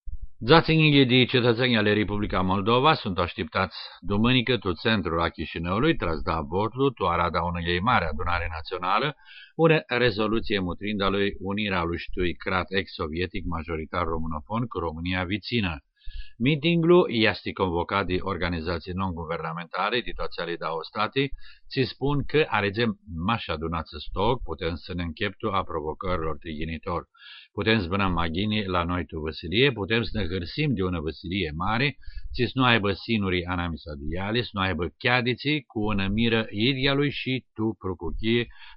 5 July 2015 at 4:14 pm Sounds like a Greek guy reading Romanian text about Moldova.
As the overall sound of this clip has, to my ear, echoes of Italian, and I hear a few incidences of /r/ realised as an alveolar approximant (which occurs in some varieties of Albanian), I am guessing that it is a dialect of Aromanian spoken on the Albanian Adriatic coast – Farsherot or Muscopolean .
Bulgaria and Serbia The recording comes from Radio România Internaţional .